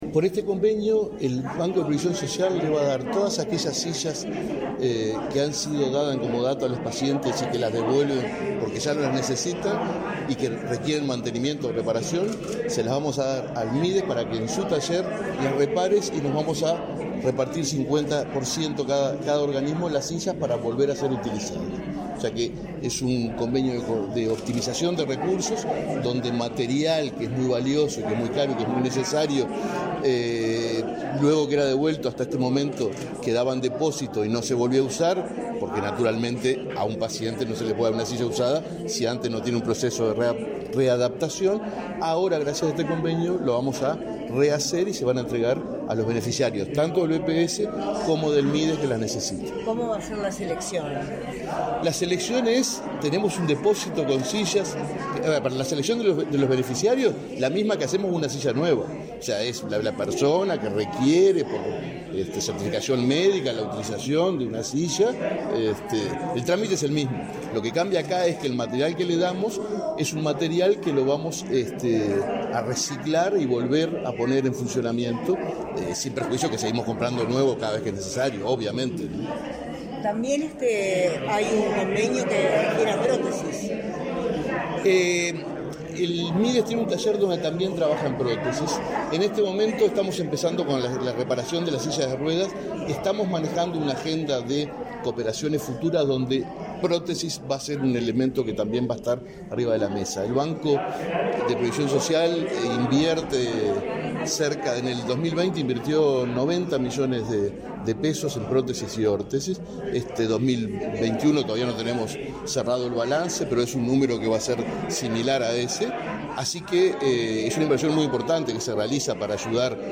Declaraciones a la prensa del presidente del BPS, Alfredo Cabrera